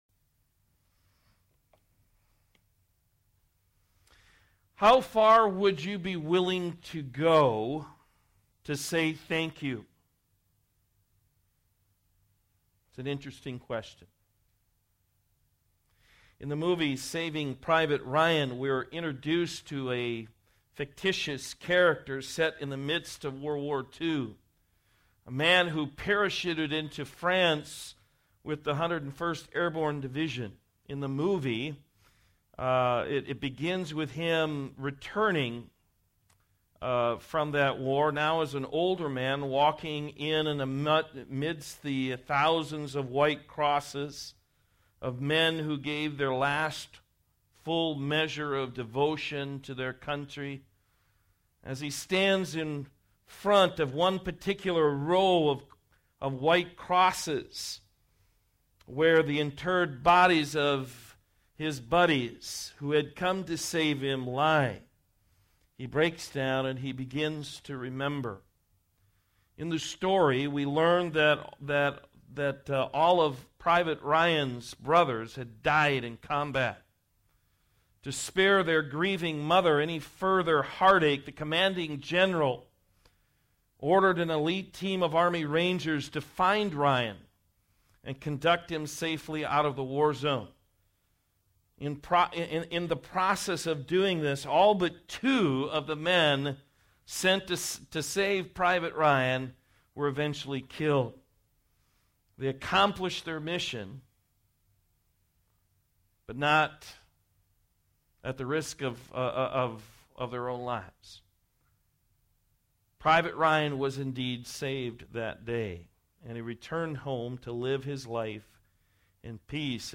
Matthew 6:25-34 Service Type: Morning Service You don't need to worry about your life